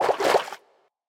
sounds / entity / fish / swim5.ogg
swim5.ogg